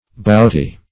boughty - definition of boughty - synonyms, pronunciation, spelling from Free Dictionary Search Result for " boughty" : The Collaborative International Dictionary of English v.0.48: Boughty \Bought"y\, a. Bending.